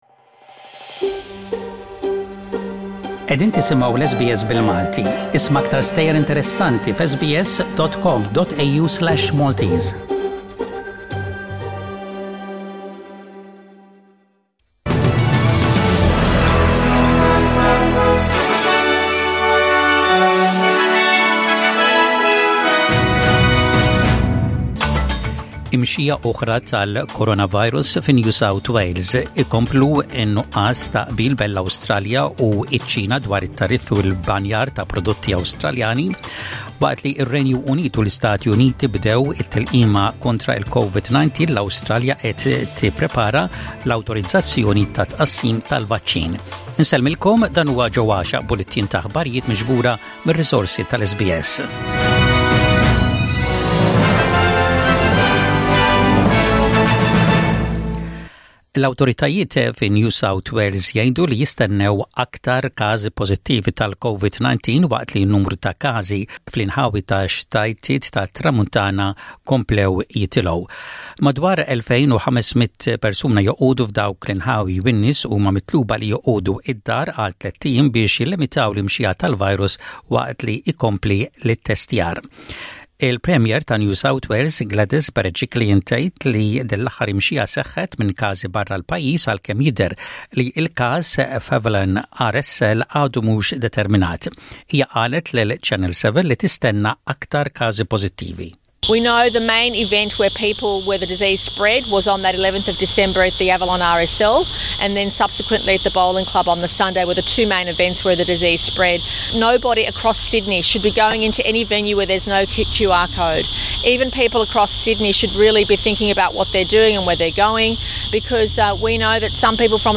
SBS Radio | Maltese News: 18/12/20